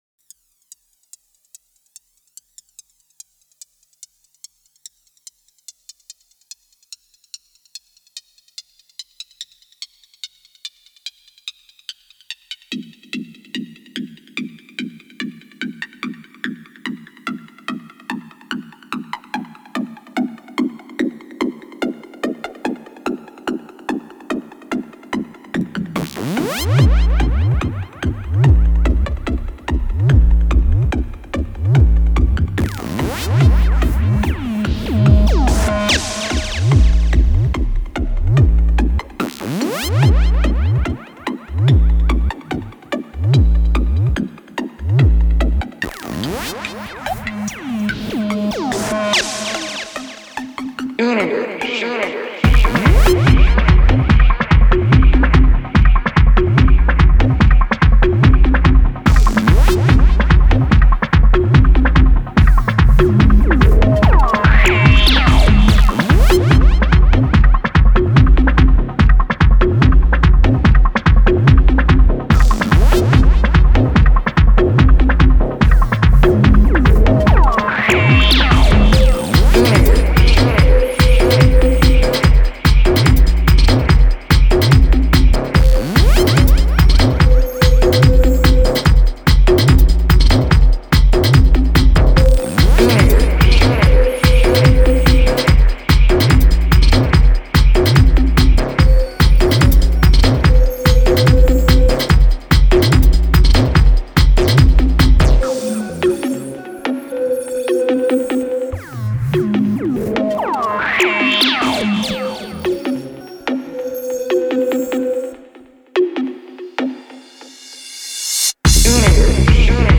house goa